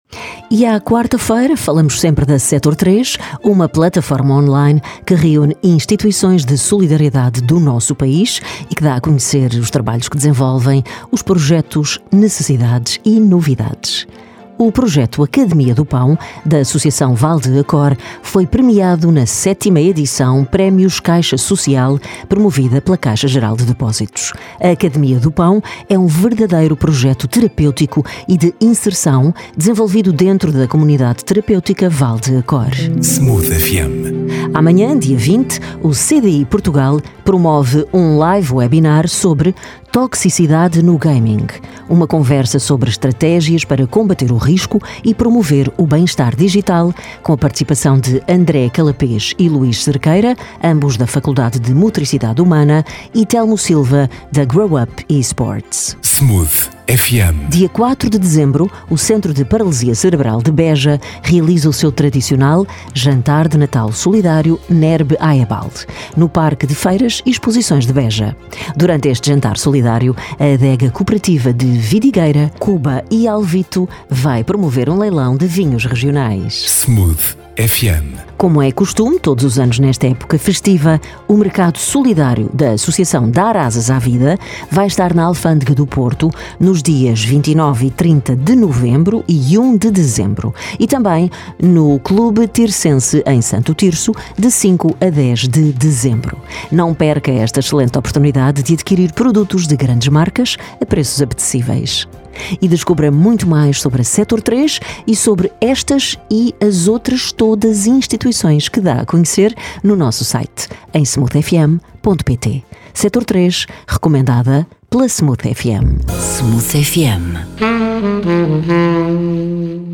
24 novembro 2025 Flash Smooth FM Associação Vale de Acór | CDI Portugal | Centro de Paralisia Cerebral de Beja | ASAS partilhar Facebook Twitter Email Apontamento rádio sobre a atividade desenvolvida por entidades-membros do Diretório Sector 3, que vai para o ar todas as quartas-feiras, às 8h, às 13h e às 17h.
Oiça a gravação do spot rádio no ficheiro anexo